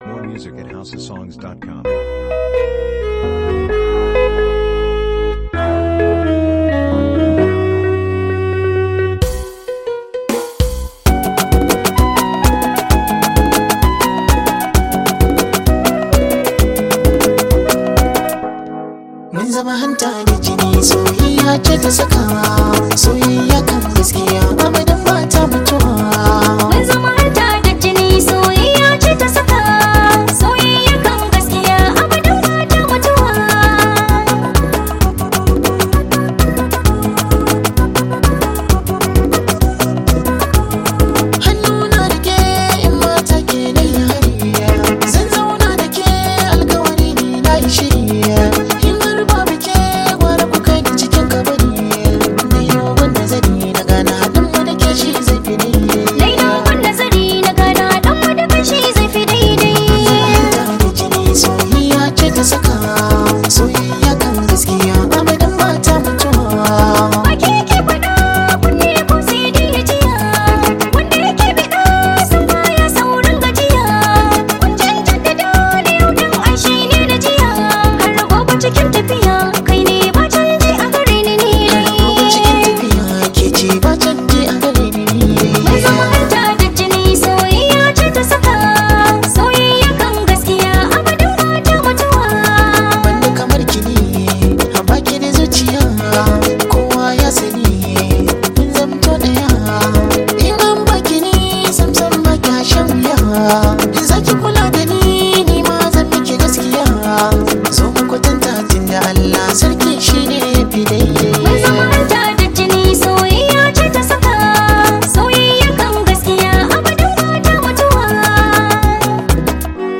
Popular hausa singer